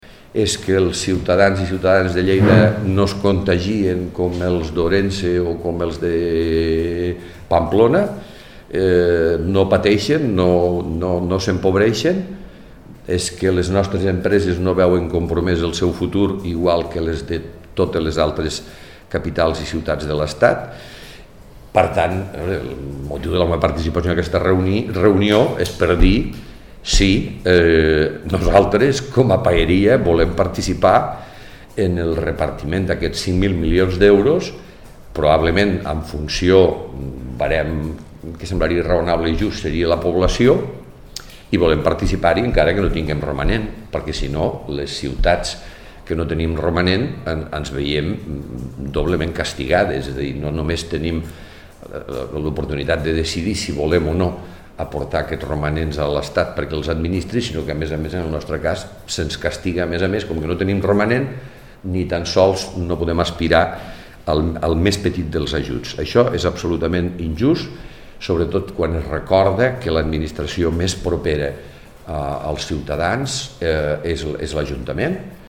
Tall de veu de l'alcalde, Miquel Pueyo, sobre reunió amb alcaldes i alcaldesses de l'Estat pel romanent positiu (1.2 MB) Comunicat conjunt d'alcaldes i alcaldesses de l'Estat contra el RD del govern espanyol i la FEMP i amb propostes per a un finançament extraordinari (50.6 KB)
tall-de-veu-de-lalcalde-miquel-pueyo-sobre-reunio-amb-alcaldes-i-alcaldesses-de-lestat-pel-romanent-positiu